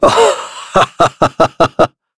Shakmeh-Vox_Human_Happy3.wav